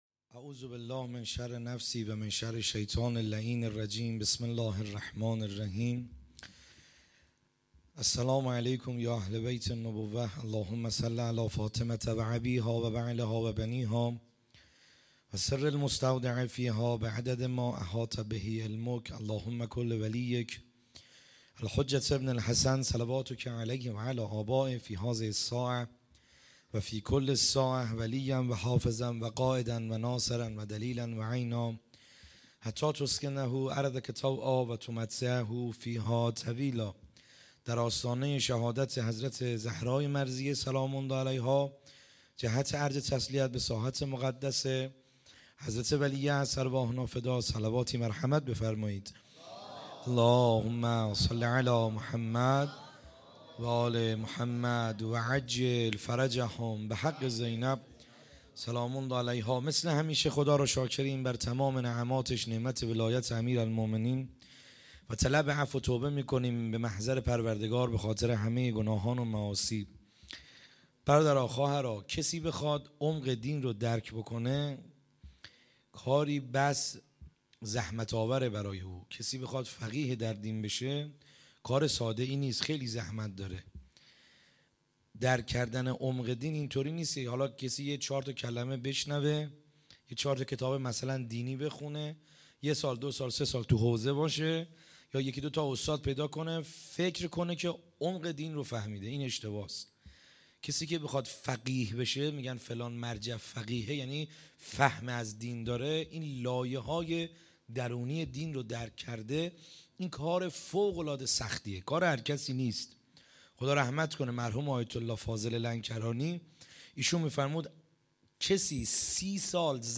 خیمه گاه - بیرق معظم محبین حضرت صاحب الزمان(عج) - سخنرانی | شب دوم